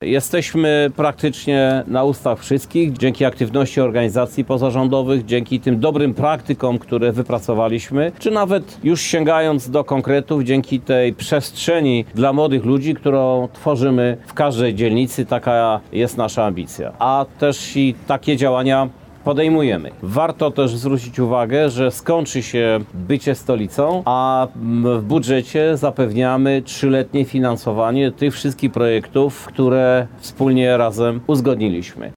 Główna aleja w Parku Ludowym otrzymała imię Europejskiej Stolicy Młodzieży Lublin 2023. Dziś (20.10) uroczyście odsłonięto tablicę z nazwą alei.
ZDJĘCIA ZDJĘCIA: – Chcemy zostawić trwały ślad po tym ciekawym roku – mówi prezydent Lublina Krzysztof Żuk.